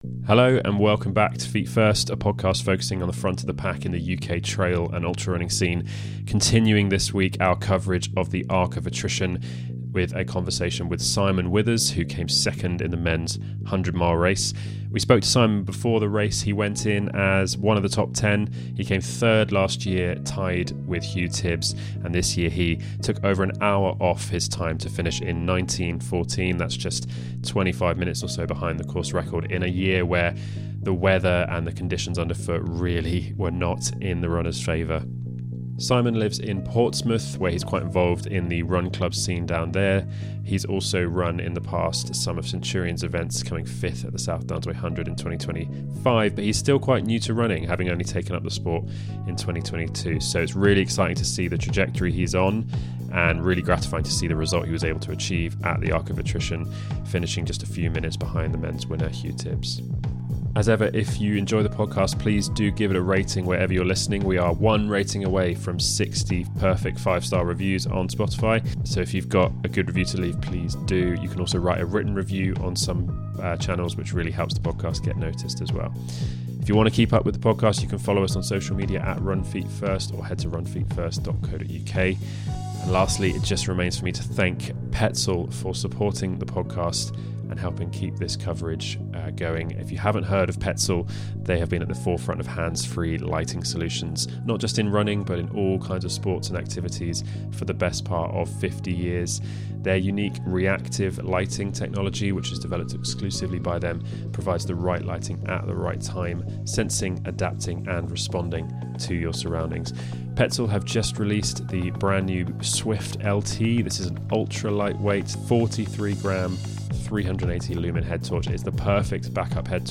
A pre- and post-race chat